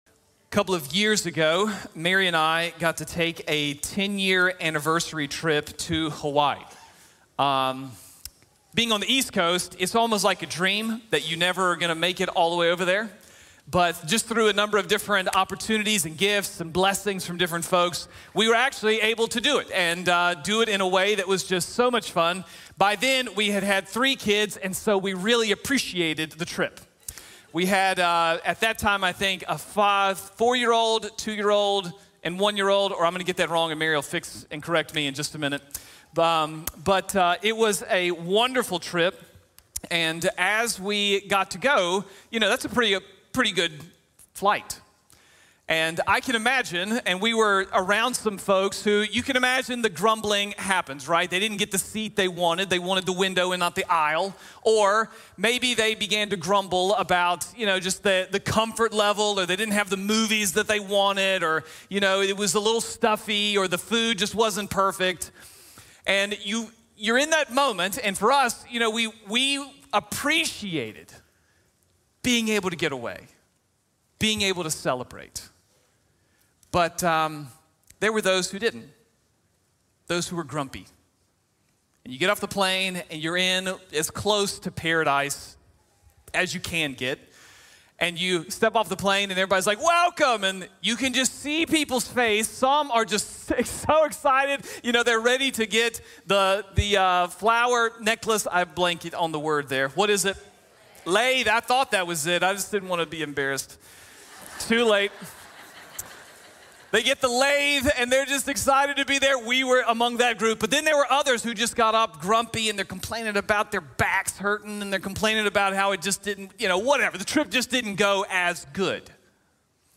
| Sermon | Grace Bible Church